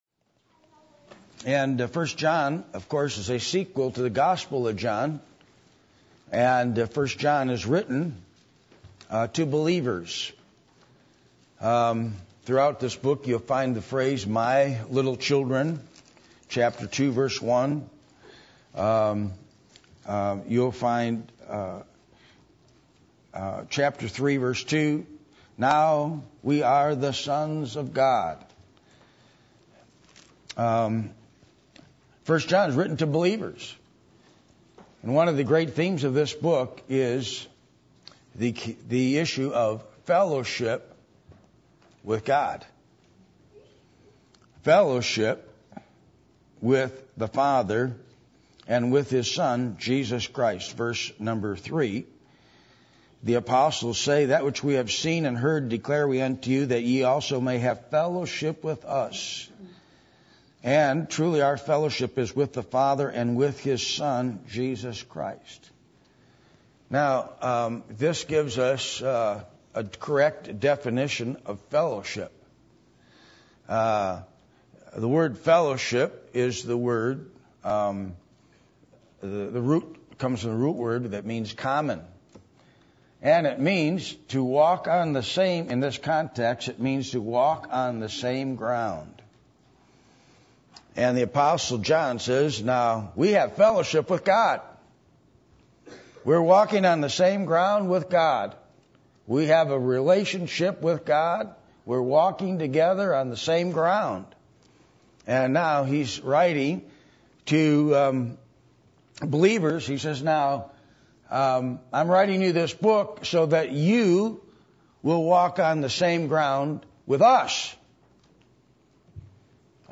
1 John 1:1 Service Type: Sunday Morning %todo_render% « Are You Heavenly Minded?